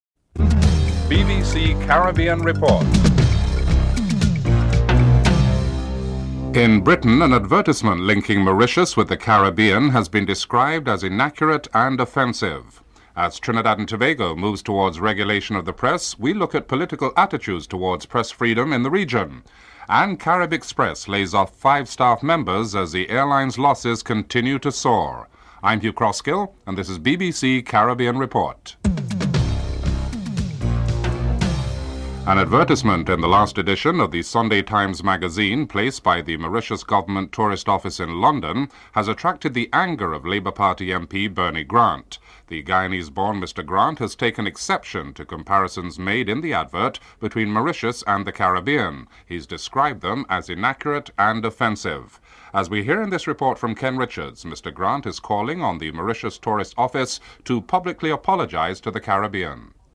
1. Headlines (00:00-00:31)
Black Labour Member of Parliament Bernie Grant is interivewed (05:18-07:14)